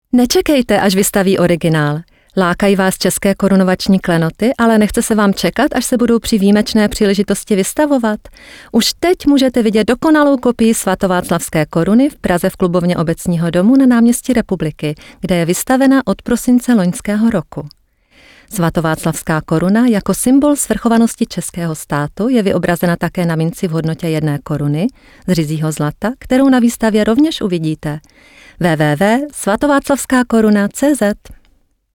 Native Sprecherin für Tschechisch
Sprechprobe: Sonstiges (Muttersprache):
native-speaker for Czech language